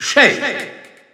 Announcer pronouncing Sheik's name in French (PAL).
Sheik_French_EU_Announcer_SSBU.wav